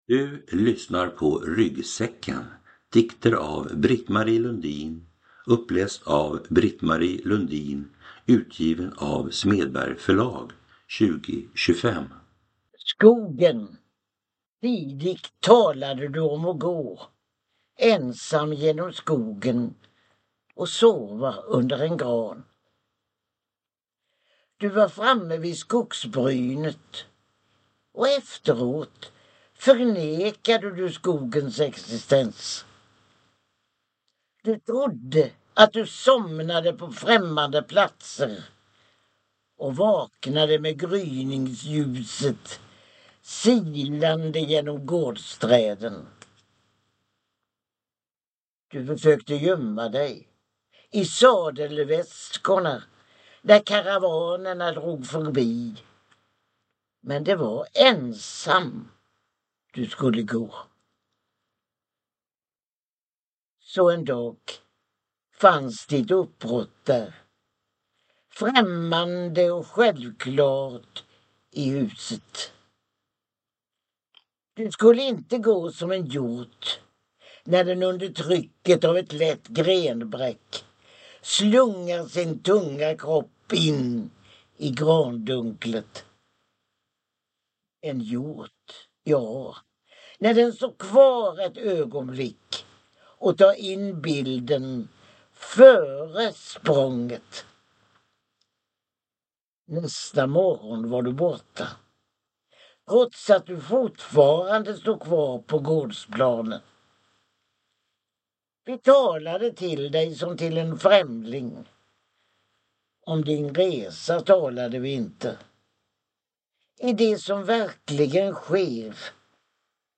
Ryggsäcken – Ljudbok
Lyrik Lyrik - Ljudböcker Njut av en bra bok Visa alla ljudböcker